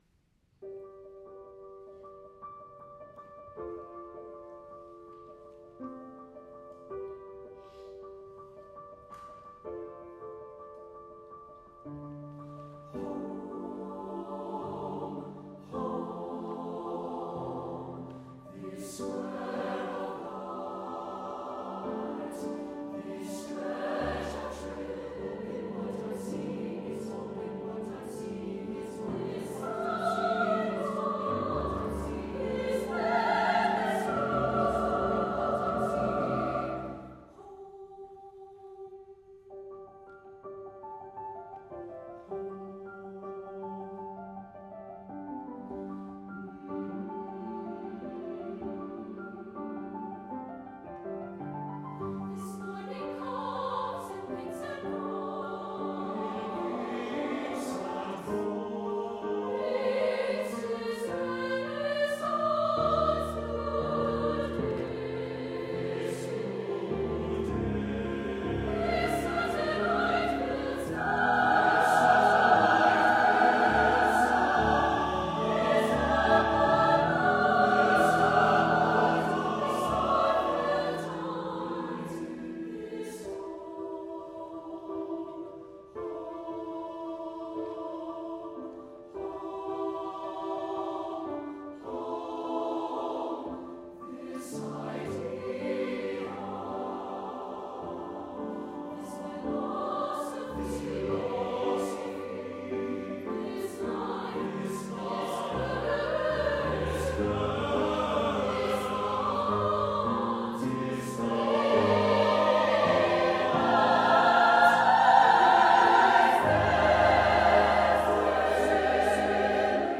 SATB div. choir and piano
nearly continuous alternation between 5/4 and 6/4 meters